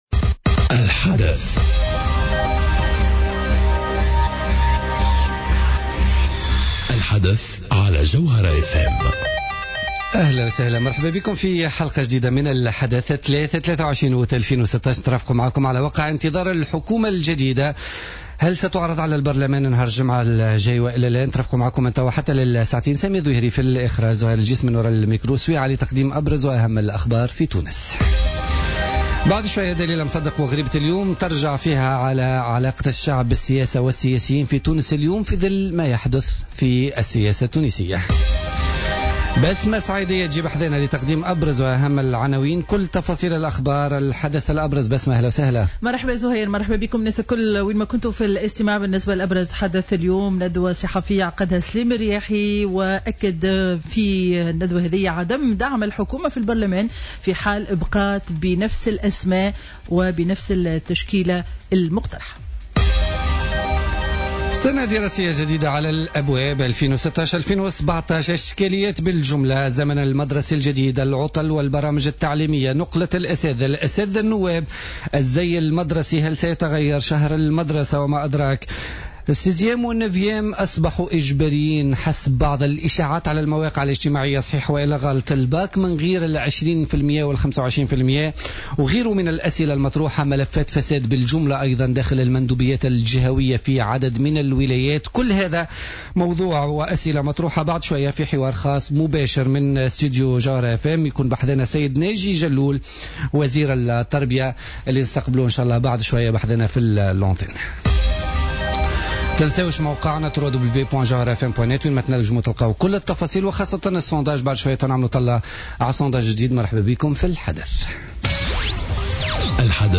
Interview avec Néji Jalloul